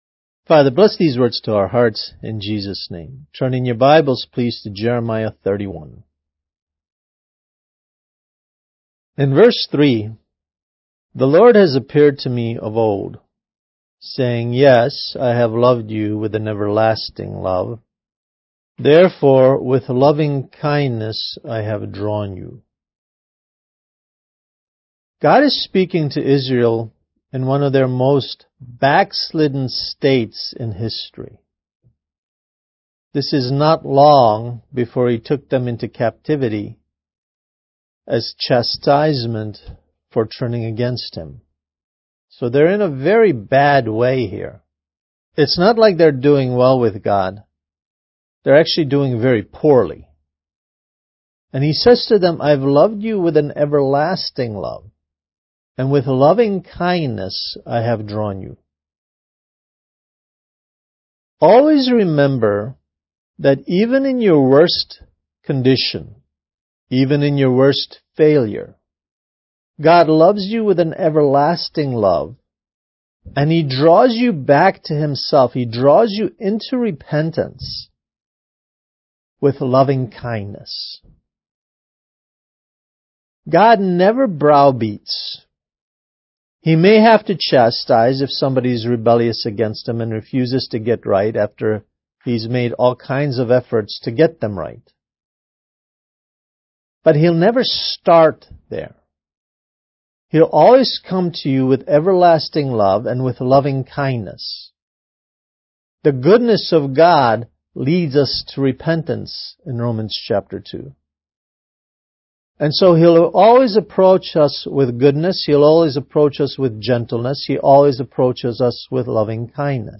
Kids Message: Learn to Let God Love You